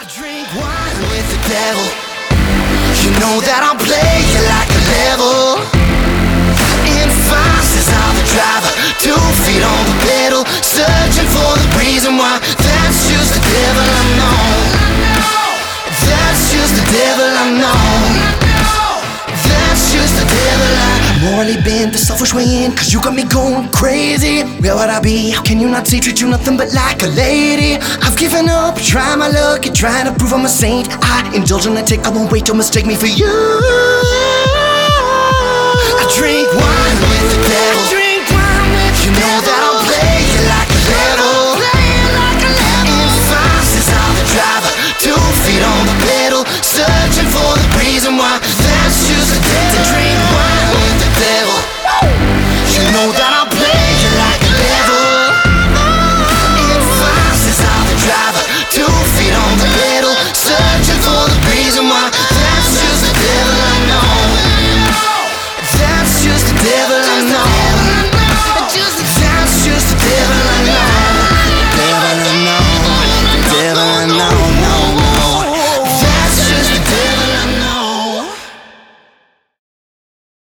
alternative
indie rock